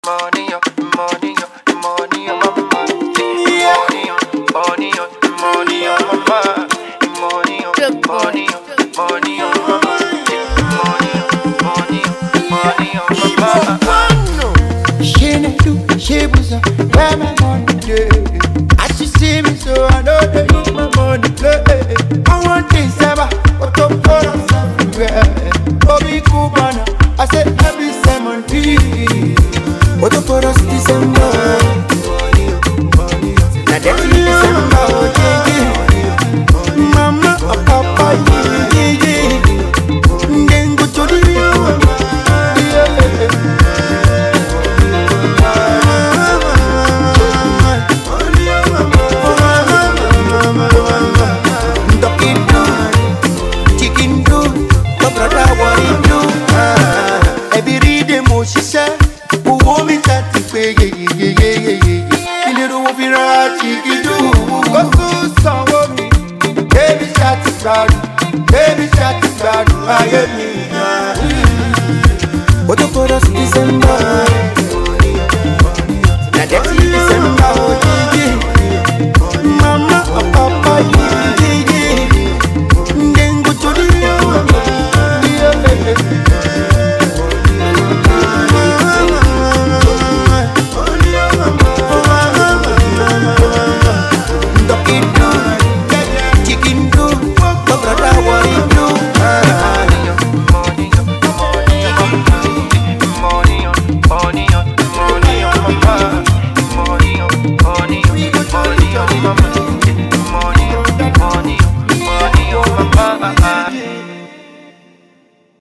Hipop rapper, singer-songwriter
fresh and energetic new song